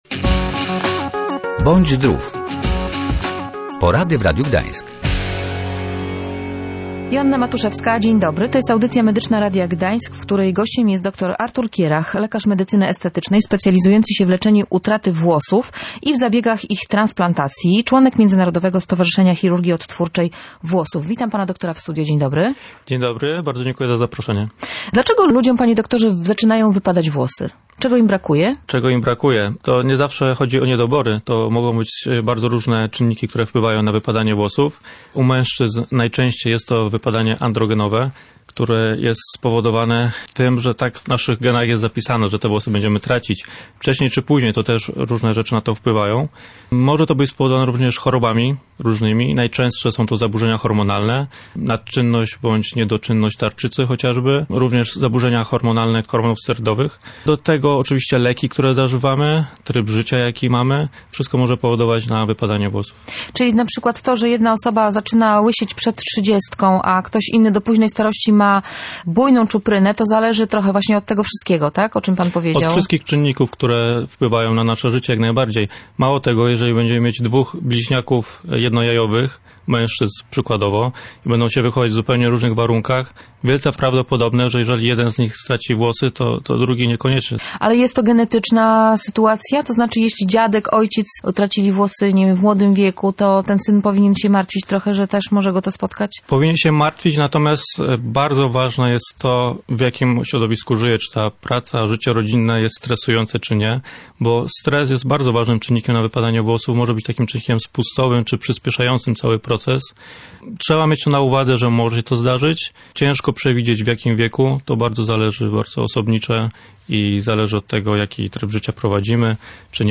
W Radiu Gdańsk mówił o metodach leczenia ich utraty